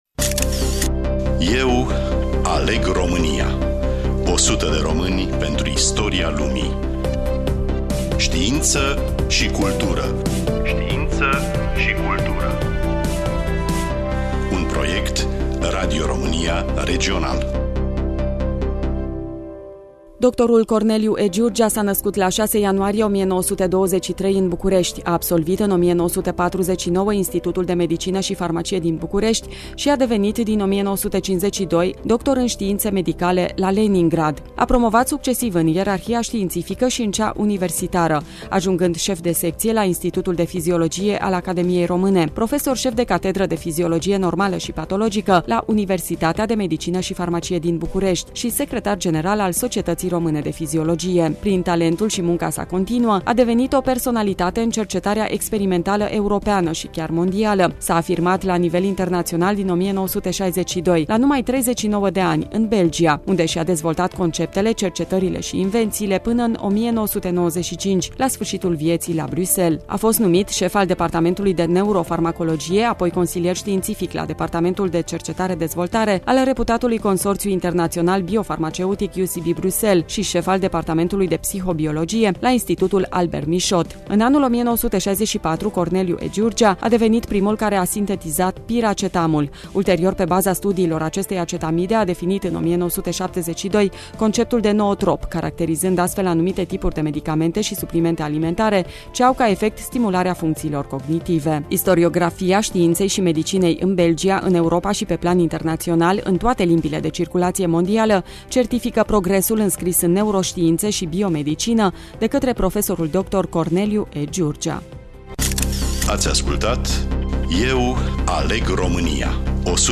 Studioul: Radio România Tg Mures